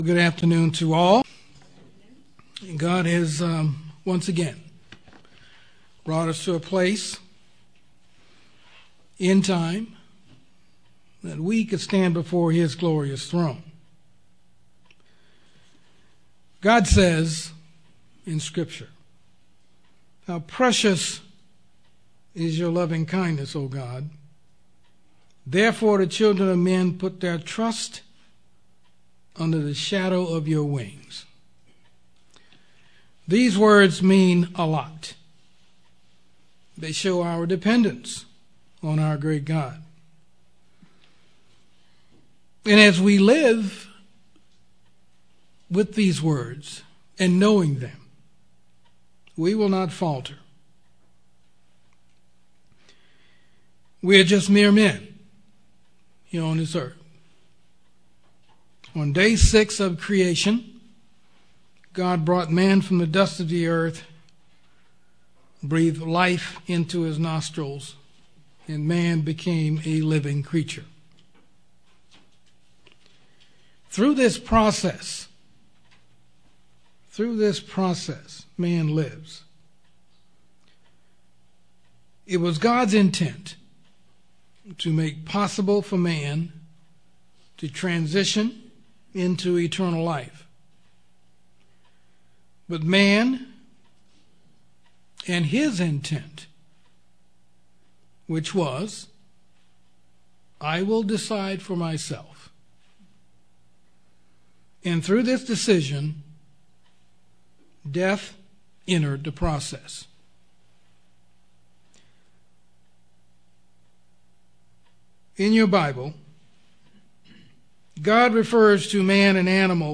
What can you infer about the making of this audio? Given in Yuma, AZ